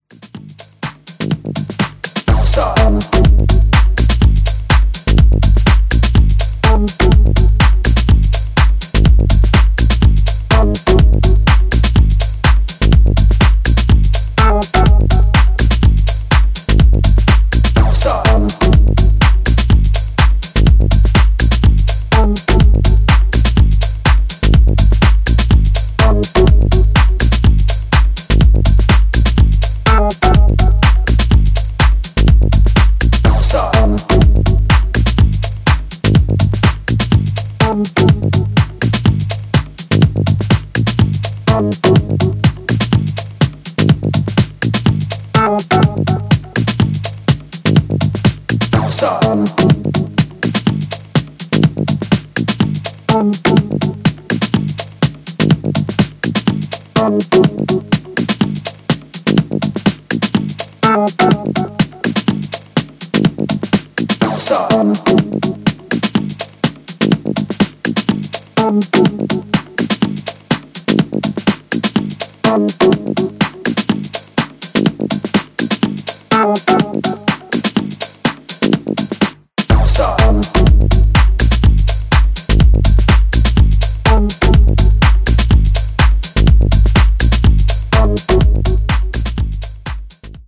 ジャンル(スタイル) MINIMAL / TECH HOUSE